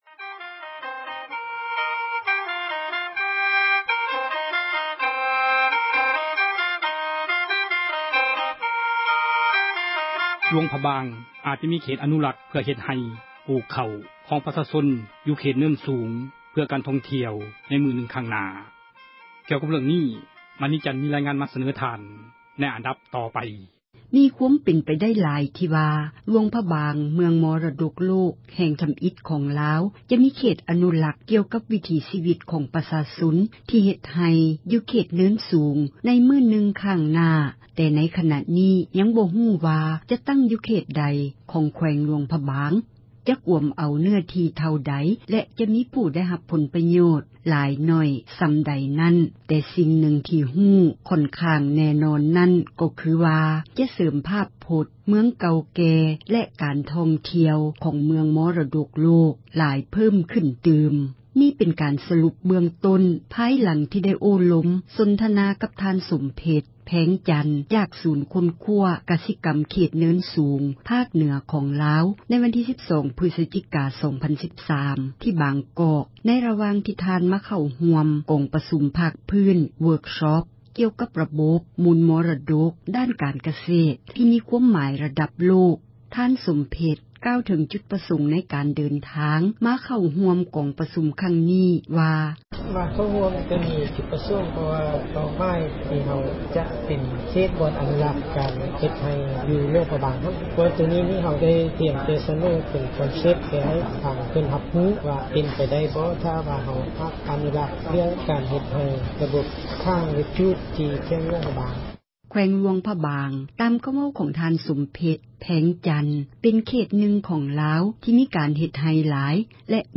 F-LP ທີ່ບາງກອກ ປະເທດໄທ: ນັກຂ່າວເອເຊັຽເສຣີ ສັມພາດ